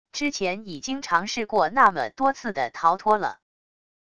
之前已经尝试过那么多次的逃脱了wav音频生成系统WAV Audio Player